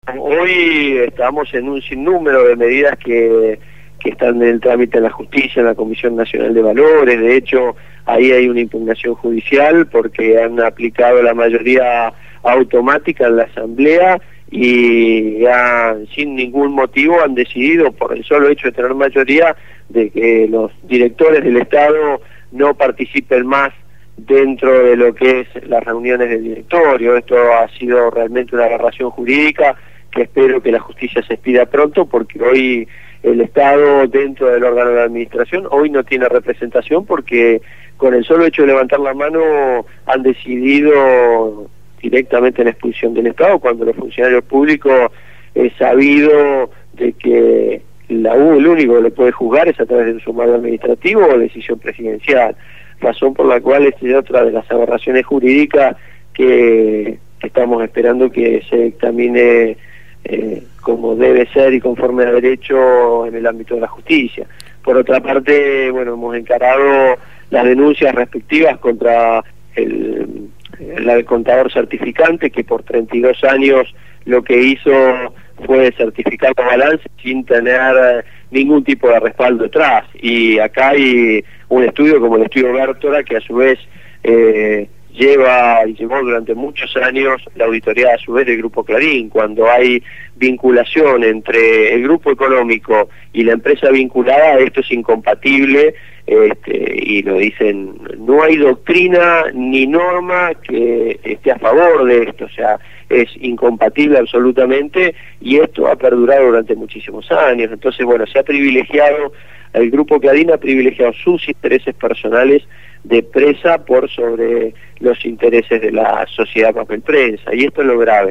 Daniel Reposo, titular de la SIGEN (Sindicatura General de la Nación) fue entrevistado